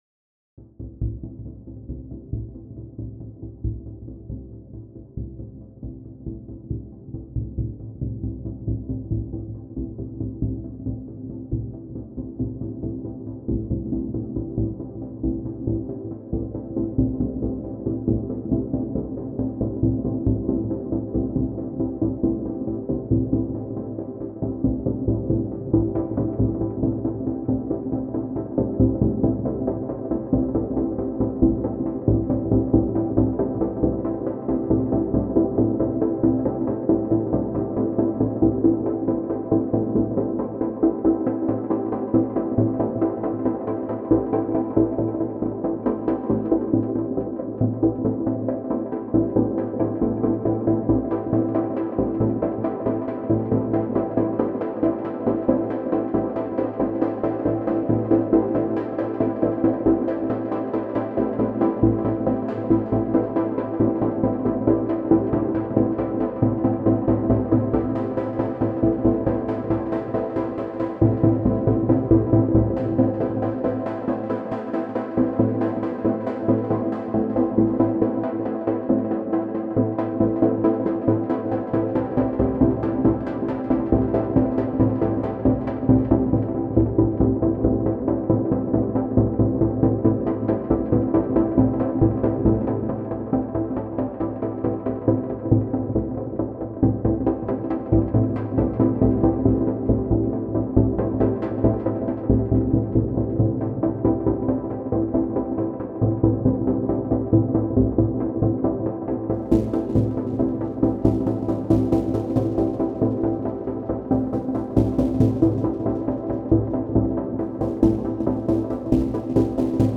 I greatly enjoy loading atmospheric samples into a SinglePlayer machine, enable RTRG on the TRIG page and have an LFO modulate SRC STRT and just keep pressing a note while manually scrubbing through the sample using the STRT parameter on the SRC page. It’s got something meditative to it and it’s fun messing around with FX and the filter.
The following has some additional modulation on the base width filter going on and has the sound drowned in some chorus, delay and reverb on the send tracks.